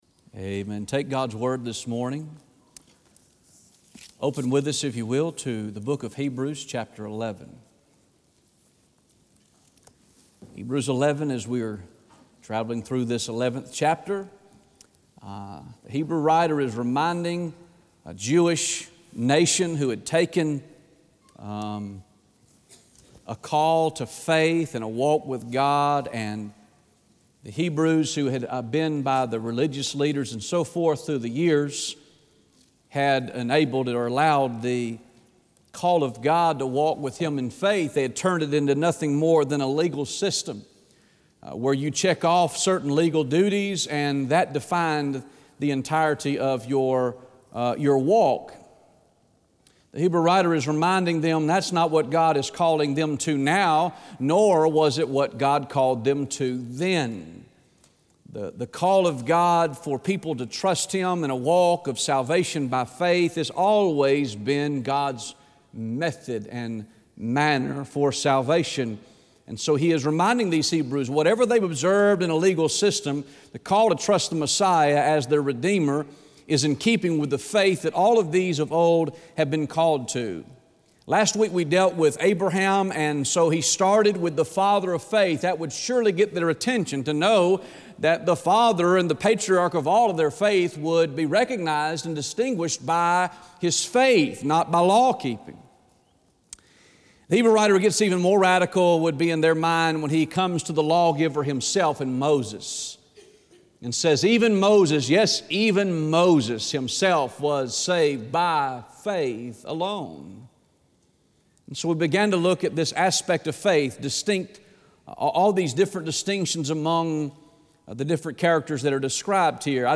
Sunday December 4th, 2016 am service